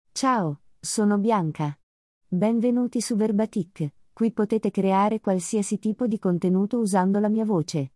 Bianca — Female Italian AI voice
Bianca is a female AI voice for Italian.
Voice sample
Female
Bianca delivers clear pronunciation with authentic Italian intonation, making your content sound professionally produced.